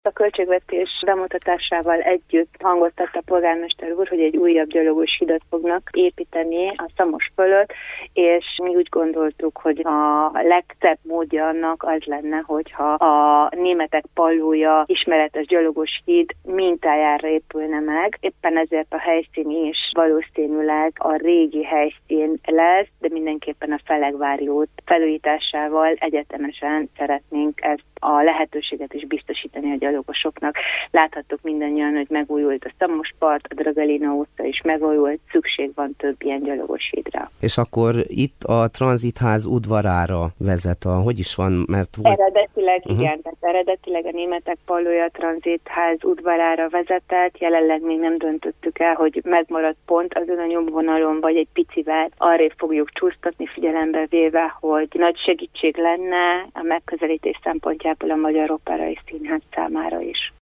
Oláh Emese alpolgármester: